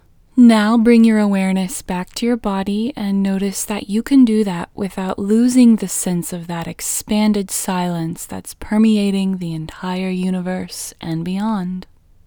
WHOLENESS English Female 16